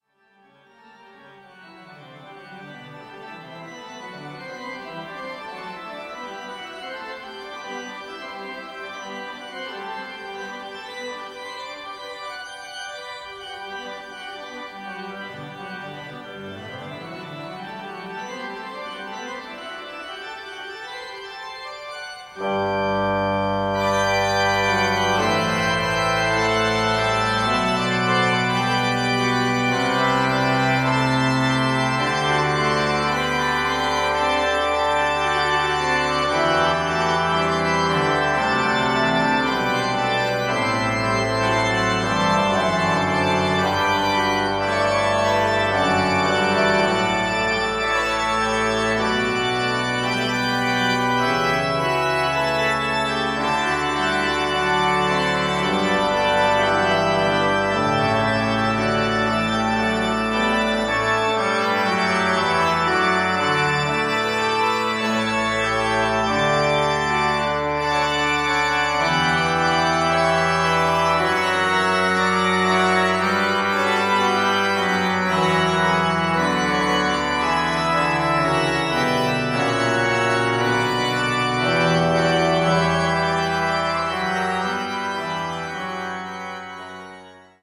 Aedian Skinner-Orgel der Riverside Church, New York